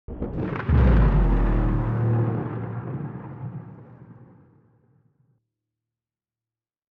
explosion